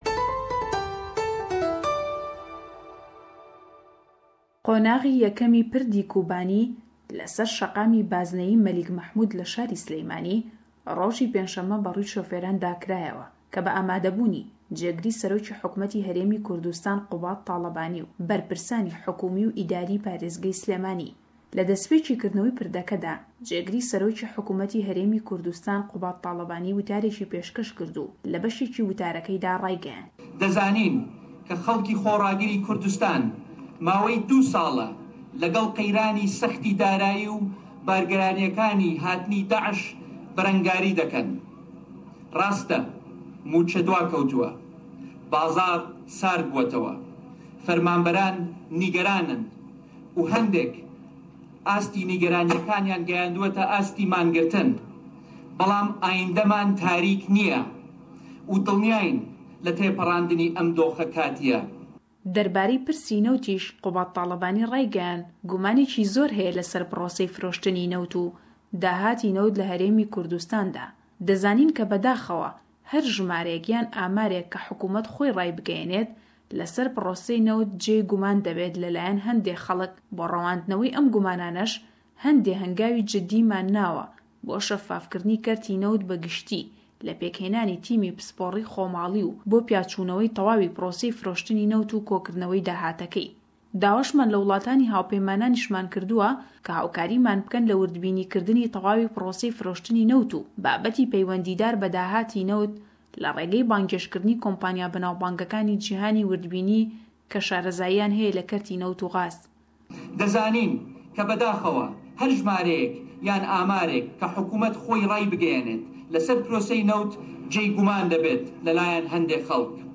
Raportî peyamnêrman le Slêmanî-yewe ke sebaret be diwa rewshî kêshekanî parlemanî hikûmetî herêmî Kurdistane, û qeyranî abûrî herêm û çendîn hewallî dike.